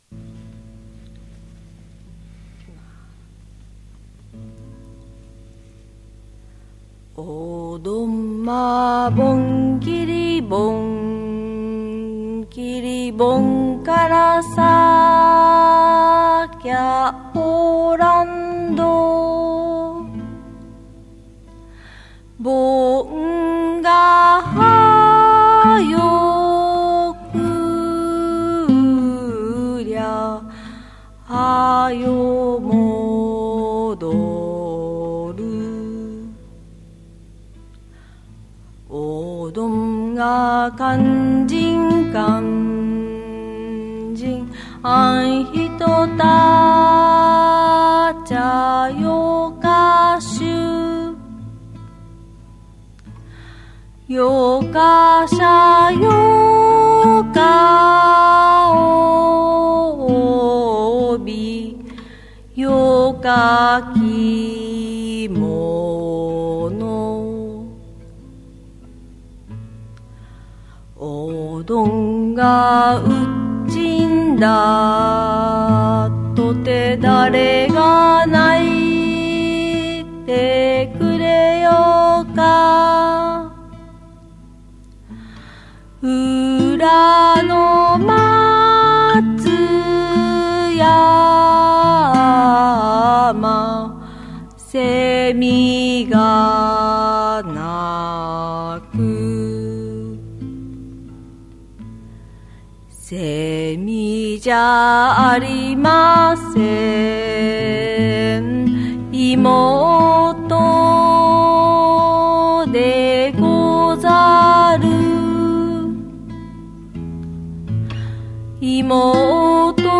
素晴らしきサイケデリック・フォーク！
凛とした寂寥感が滲み出た傑作！